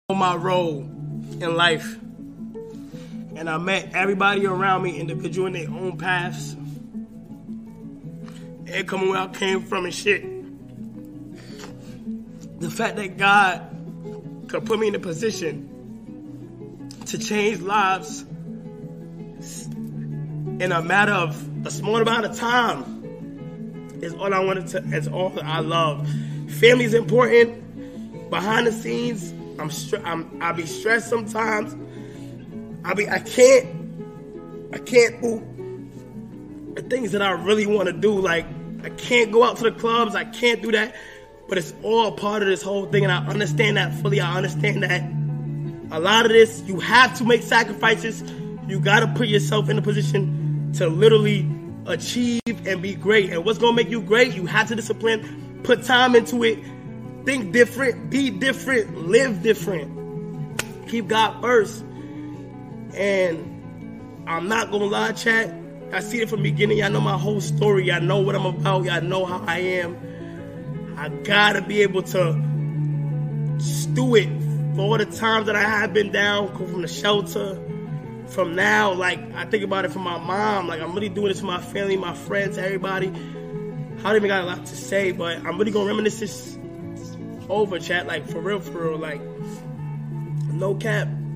kai speechW Kai Cenat For sound effects free download